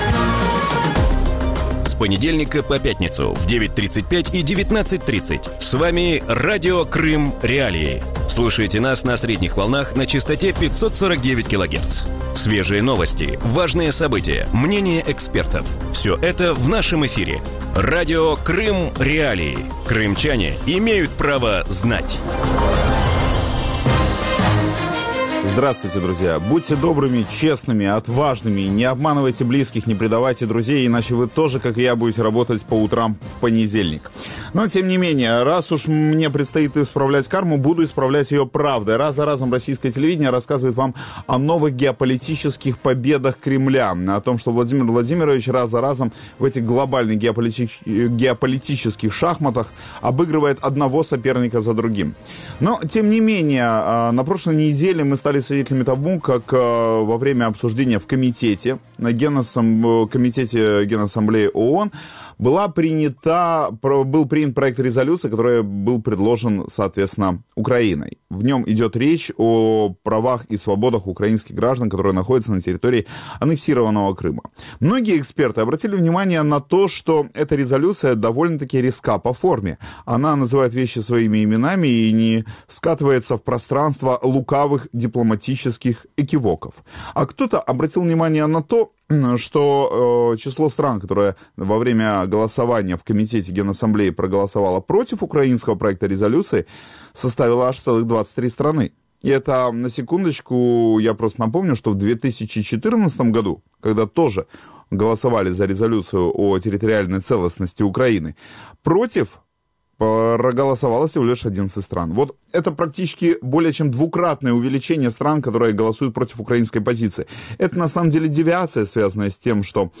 Резолюція, ухвалена комітетом Генеральної асамблеї ООН з прав людини в Криму, з великою часткою ймовірності буде схвалена на грудневому голосуванні, може спостерігатися незначна зміна думок. Таку позицію в ранковому ефірі Радіо Крим.Реалії висловив постійний представник України при Раді Європи...